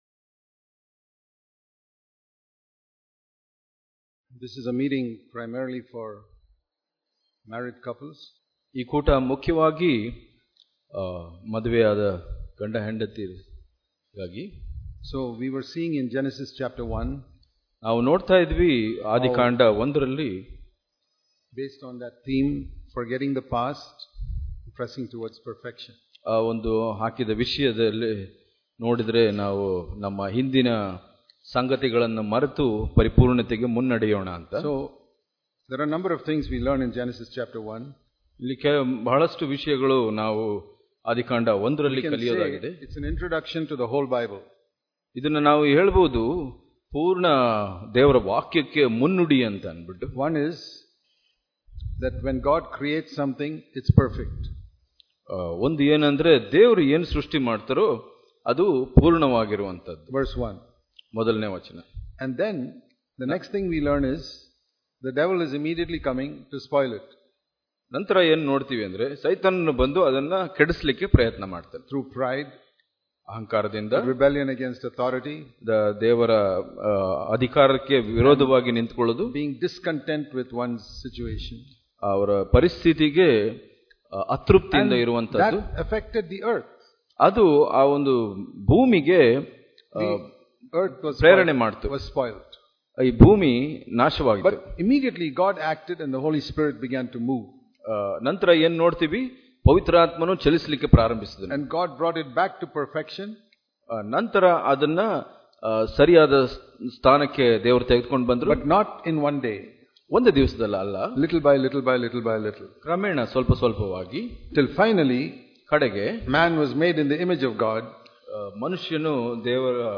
Hubli Conference 2018